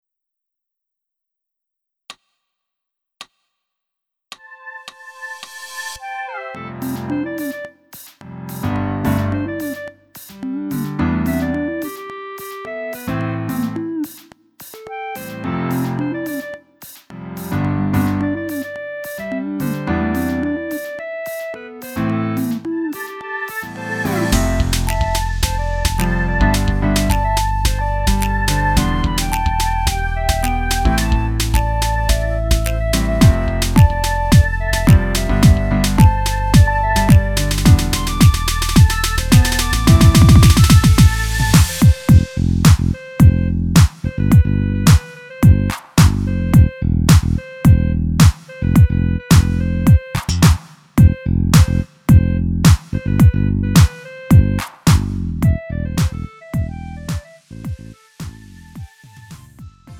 음정 원키 3:08
장르 구분 Lite MR